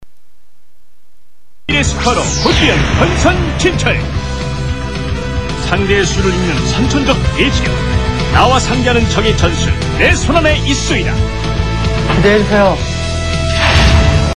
어디 게임음악 같은데요...게임음악 동호회가셔서 물어보세요^^;
게시판 음악자료 성우 목소리뒤에 깔리는 음악좀 알수 있을까요?
2007-07-06 오전 10:59:00 뒤깔리는 배경음악이 너무 좋아서 찾으려 했는데.